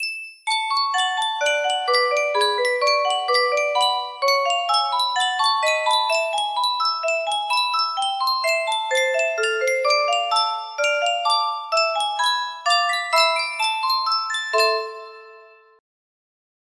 Yunsheng Music Box - Brahms Piano Sonata No. 3 6011 music box melody
Full range 60